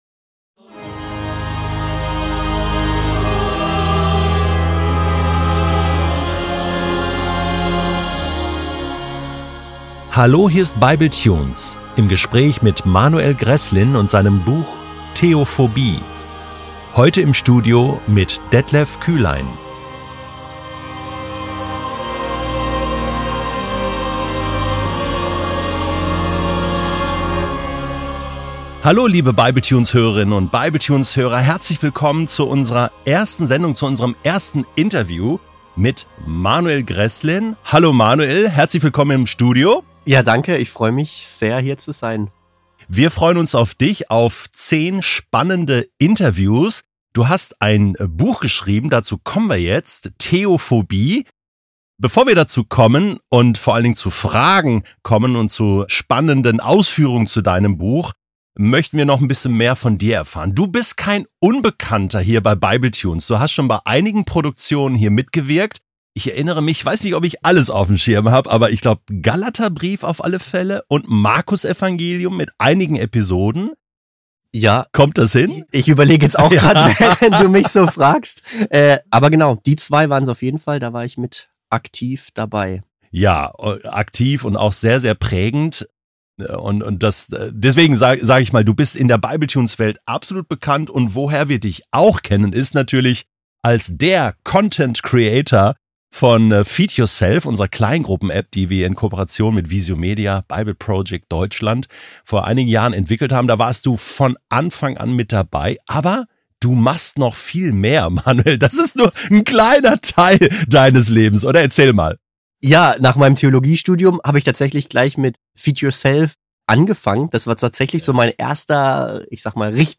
Im Gespräch wird deutlich, dass wir uns häufig ein festes Bild von Gott machen – und verunsichert sind, wenn dieses ins Wanken gerät.